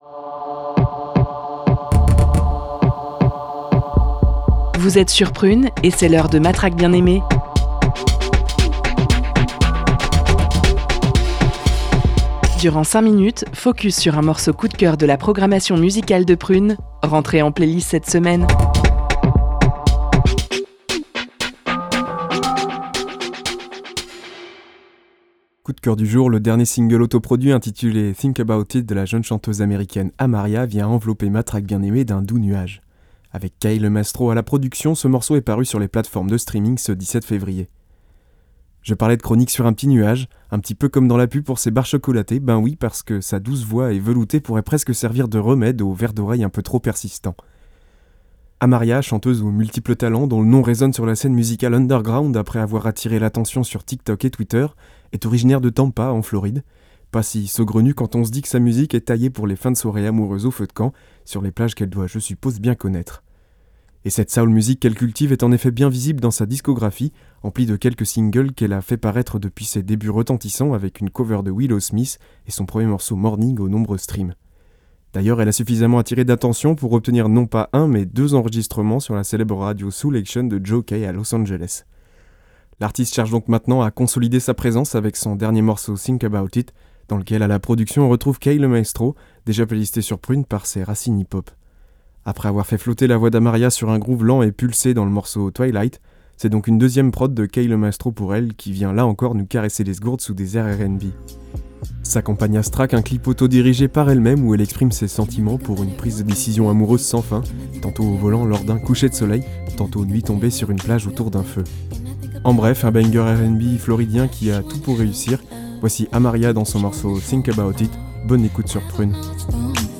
jeune chanteuse américaine
nuage vaporeux, teinté de groove